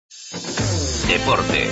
Programa deportivo con actualidad del deporte malagueño. Incluye la tertulia malaguista y programa de Kedeke Sports.